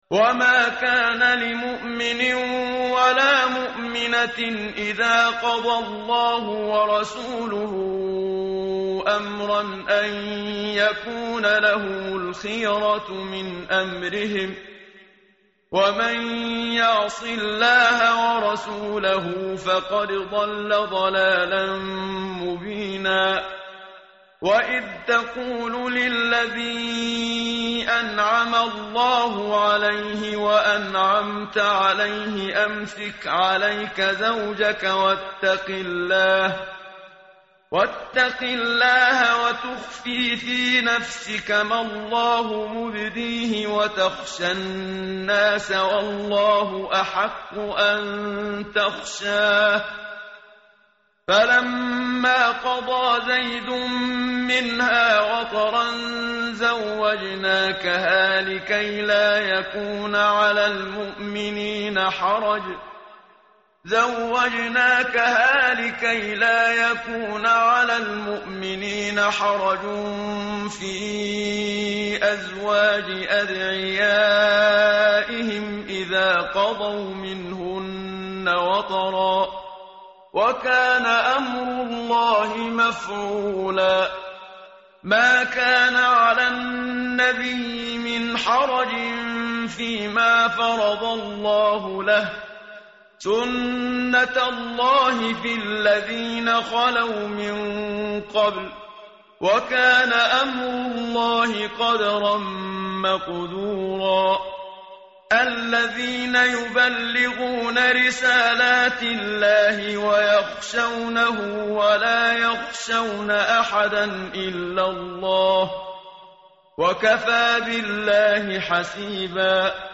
متن قرآن همراه باتلاوت قرآن و ترجمه
tartil_menshavi_page_423.mp3